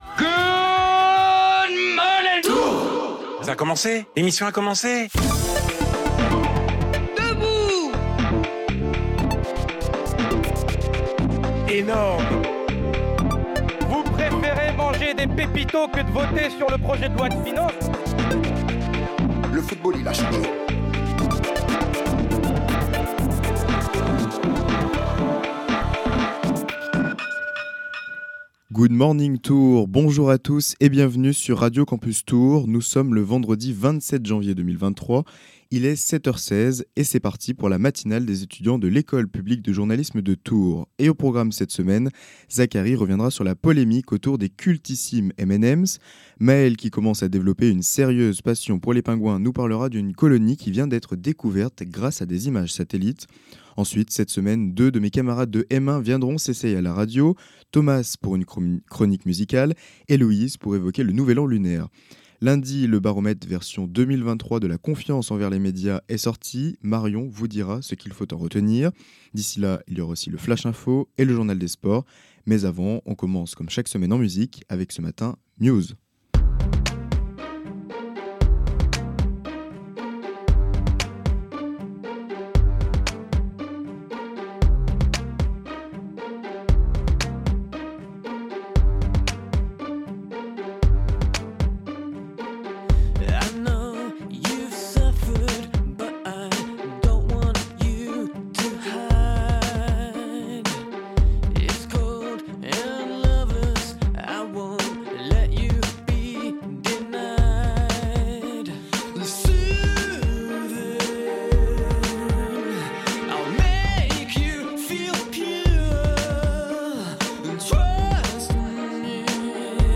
La matinale des étudiants de l’École Publique de Journalisme de Tours, le vendredi de 7h15 à 8h15 .